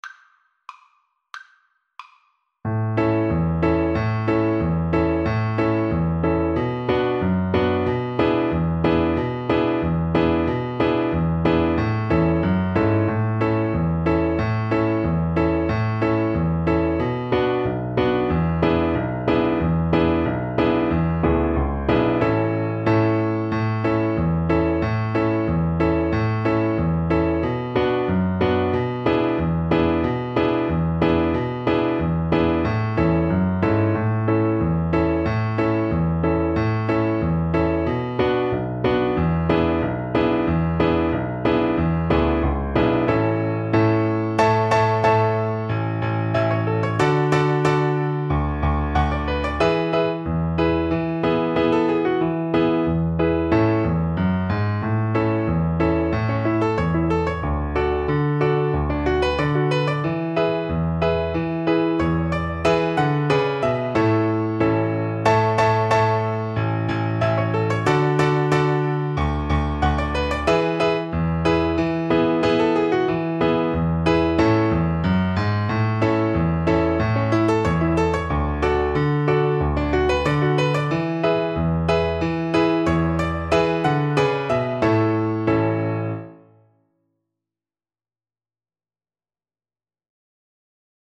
Traditional Music of unknown author.
2/4 (View more 2/4 Music)
Moderato =c.92
A major (Sounding Pitch) (View more A major Music for Violin )